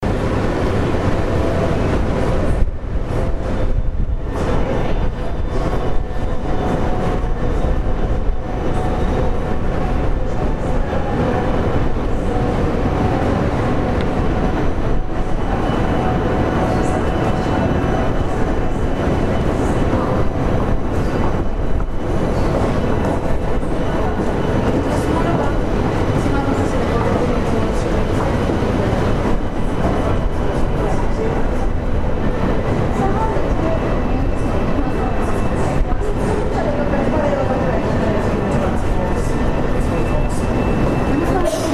The wind at the top of The Shard
Short audio from Level 72 of The Shard in London, the highest of the two public viewing galleries and the one open to the elements with no roof.